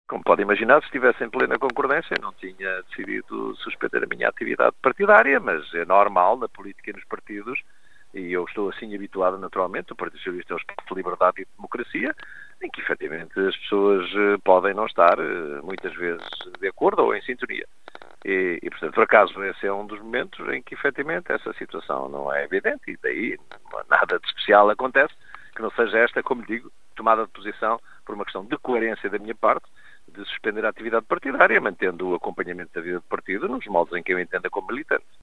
O socialista Jorge Fão em declarações à Rádio Caminha.